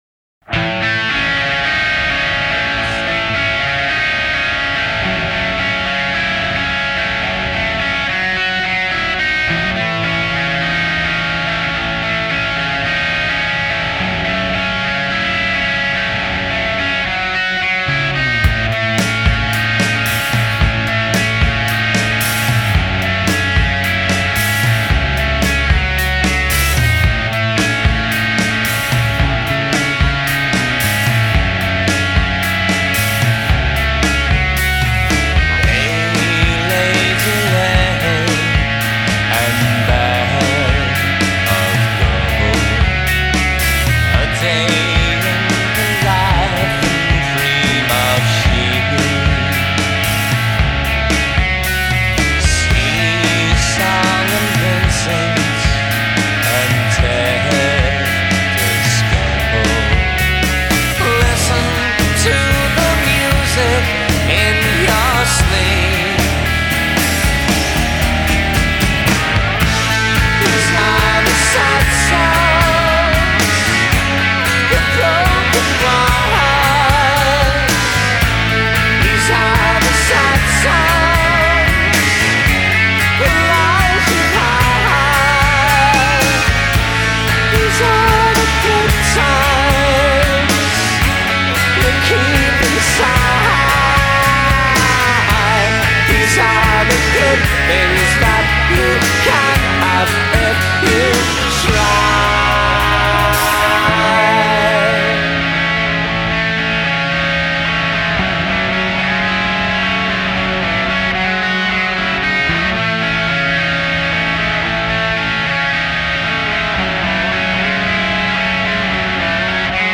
a mid-paced arty rocker that